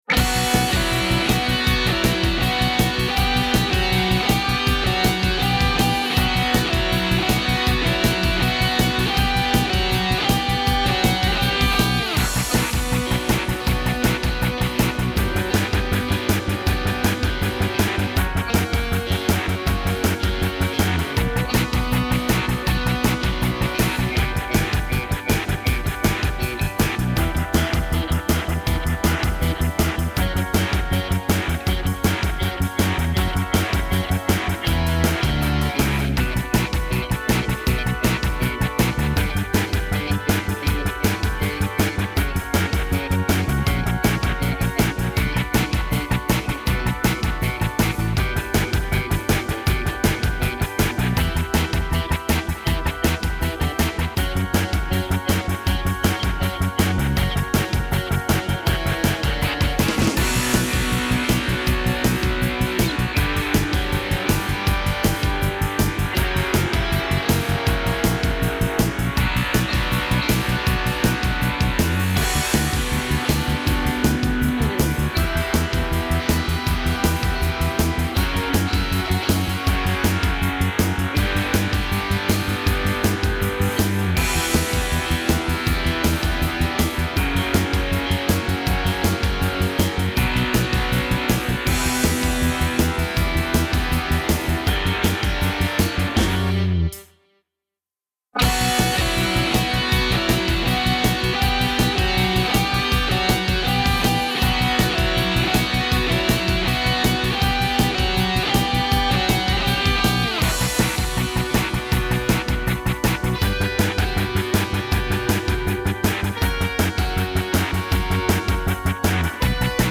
минусовка версия 225981